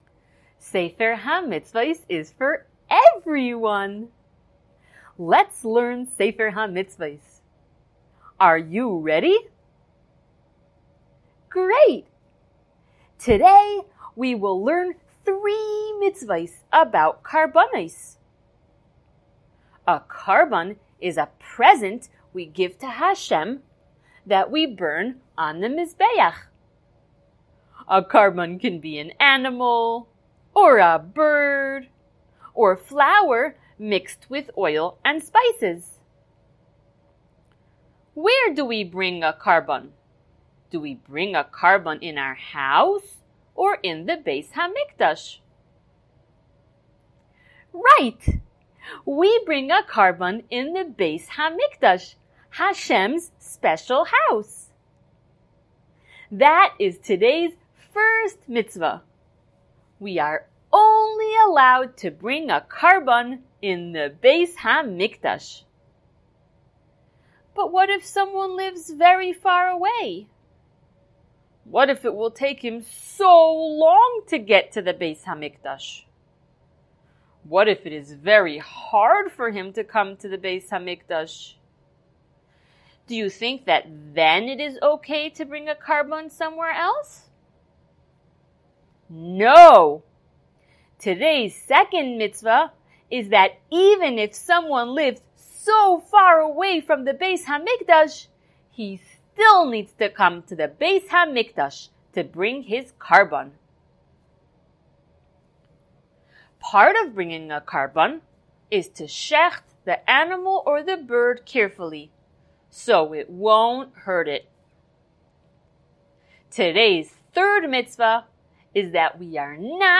Color Shiur #164!
SmallChildren_Shiur164.mp3